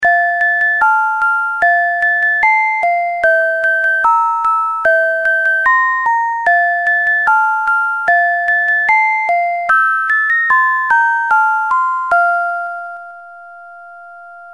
• Dual channel melody with the timber of music box